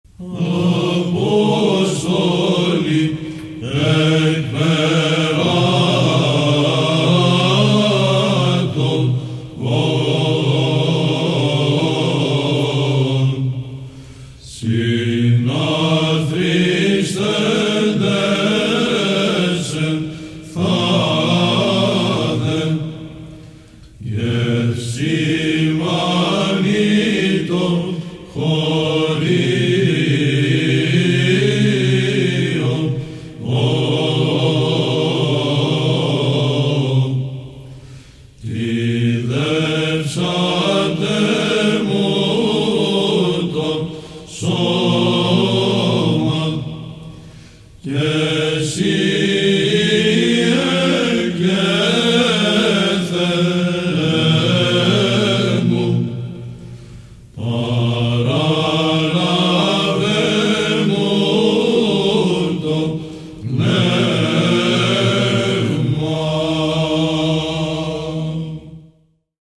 Ήχος γ'